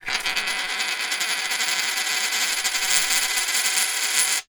Coin Spinning 2 Sound
household